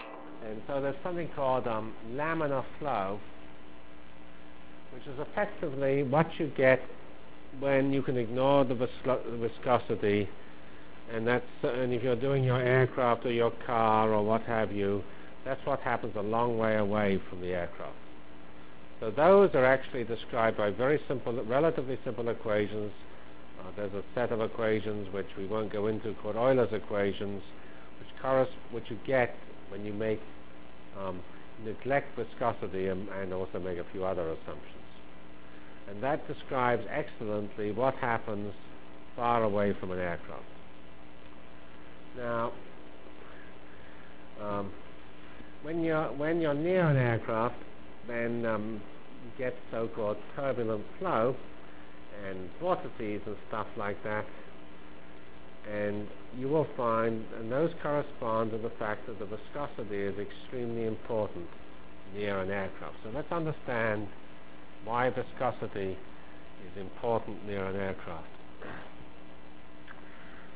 Delivered Lectures of CPS615 Basic Simulation Track for Computational Science -- 14 November 96.